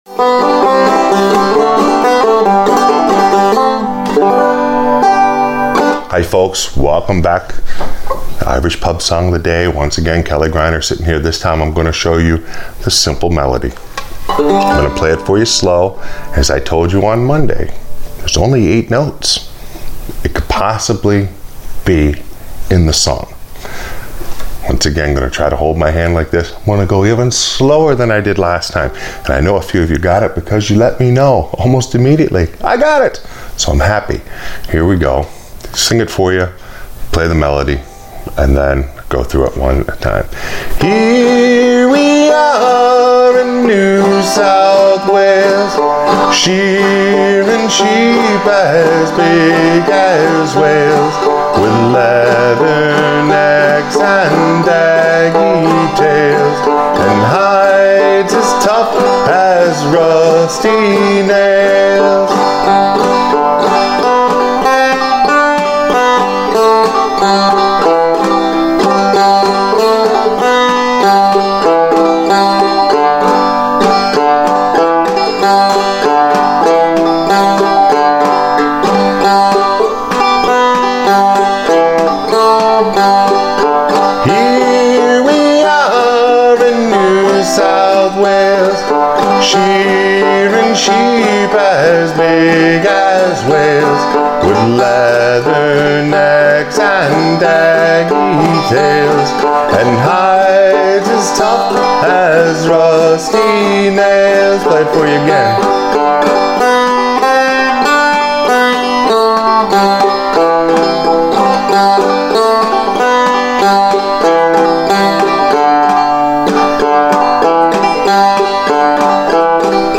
Irish Pub Song Of The Day – New South Wales – simple melody